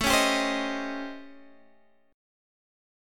A7#9b5 chord